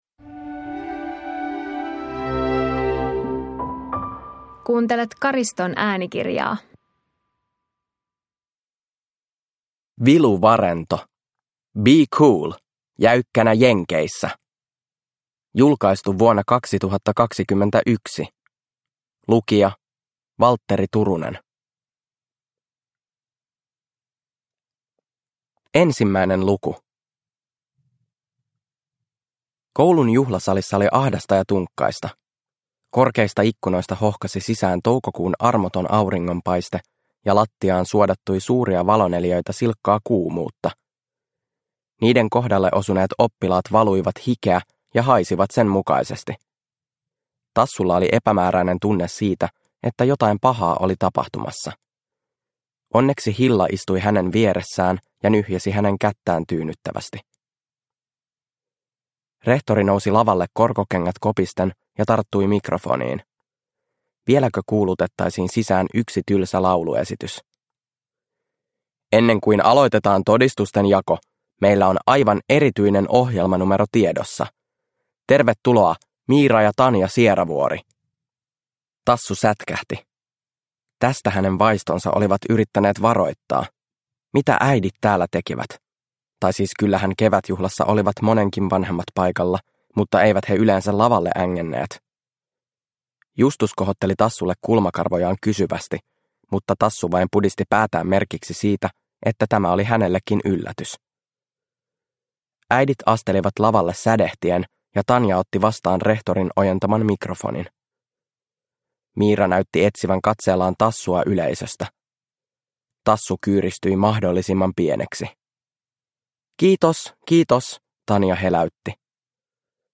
Be Cool - Jäykkänä jenkeissä – Ljudbok – Laddas ner